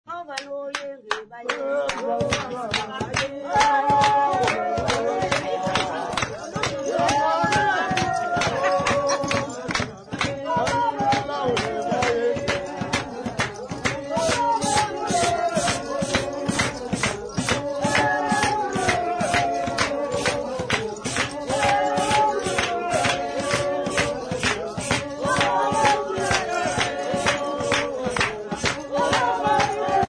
Hogsback festival participants
Folk music
Sacred music
Field recordings
Xhosa music at Hogsback festival
96000Hz 24Bit Stereo